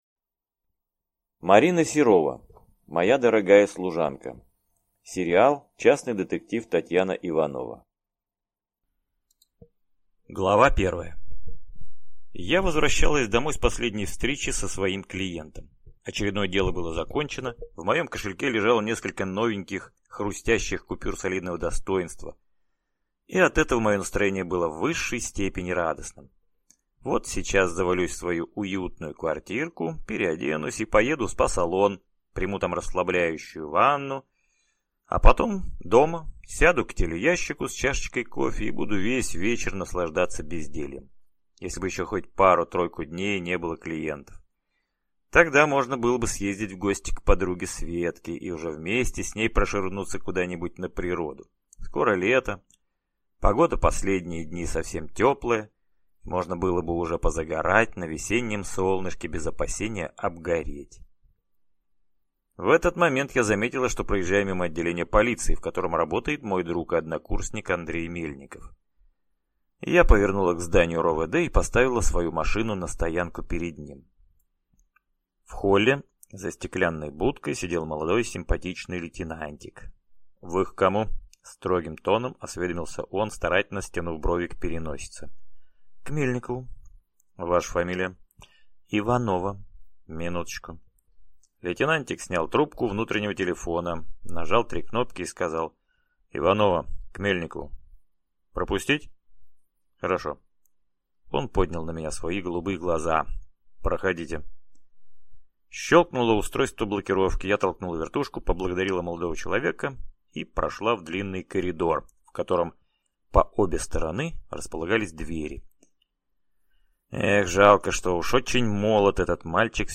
Аудиокнига Моя дорогая служанка | Библиотека аудиокниг